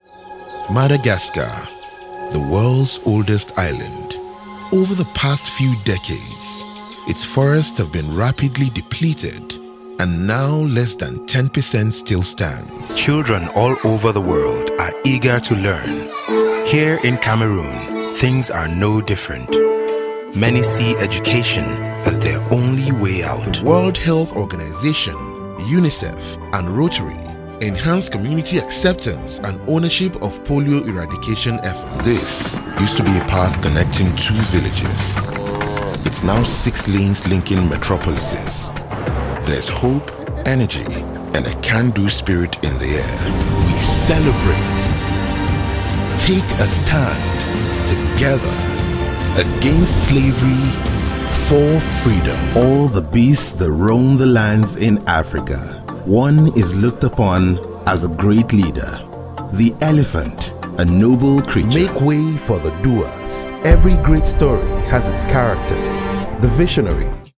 English (African)
Narration
-Sennheisser MKH 416
BaritoneBassDeepLowVery Low
ConversationalFriendlyWarmTrustworthyReliableExperiencedCorporateEngagingDynamicHappyKnowledgeableConfidentArticulateBelievableRelatableYoungEducatedCalmIntelligentInformativeSoothingNaturalHumorous